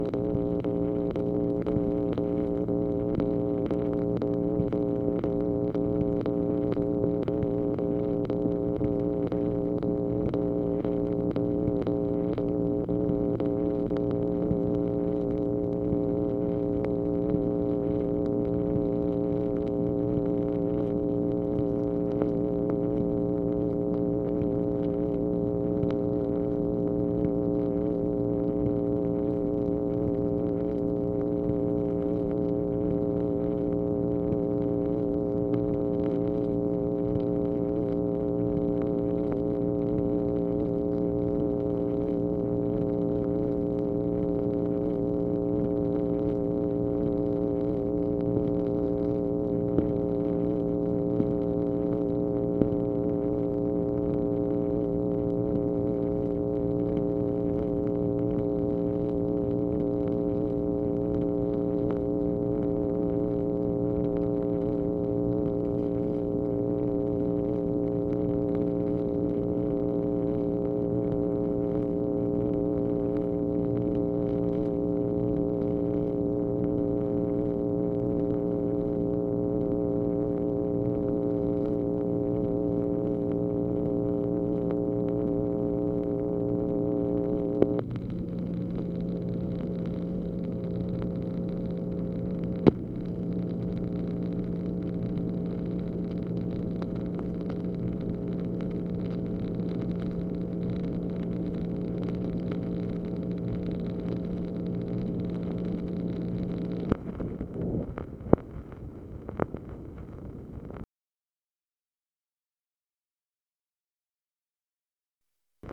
MACHINE NOISE, March 18, 1965
Secret White House Tapes | Lyndon B. Johnson Presidency